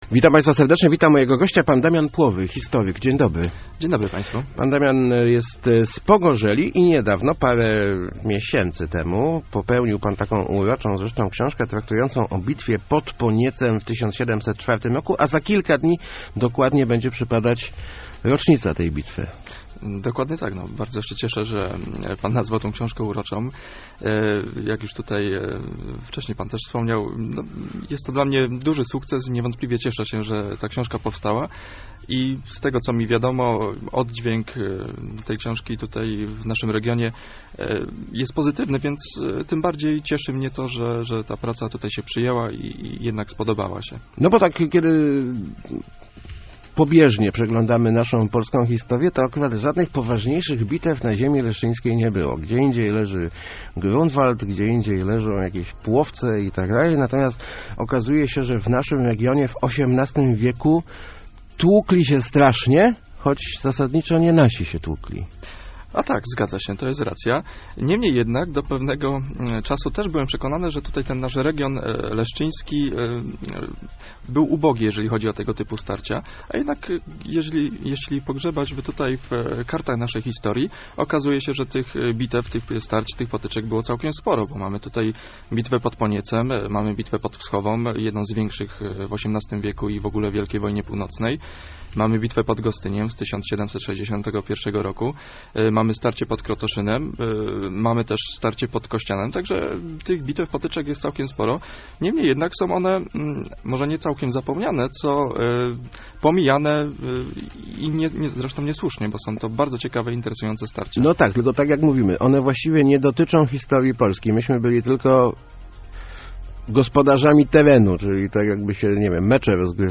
Start arrow Rozmowy Elki arrow Zapomniana wielka bitwa